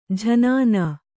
♪ jhanānā